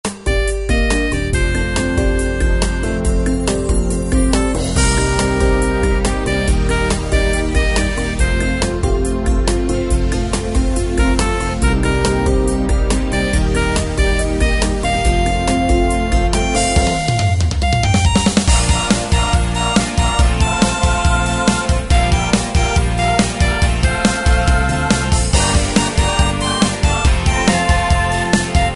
Tempo: 140 BPM.
MP3 with melody sample 30s (0.5 MB)free
MP3 with lead melody play melody usually by flute.